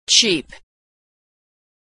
Homophones - Authentic American Pronunciation
same pronunciation